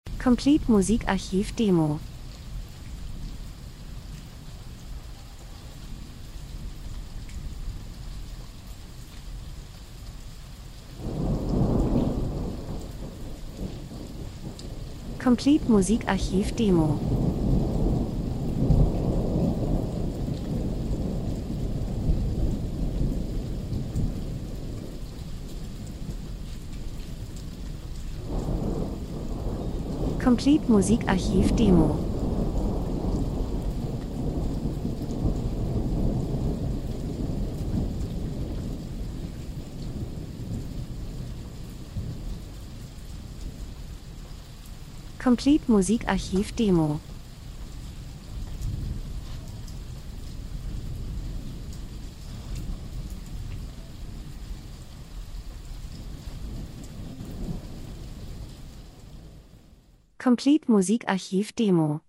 Herbst -Geräusche Soundeffekt Herbstwald, Regen, Gewitter 01:01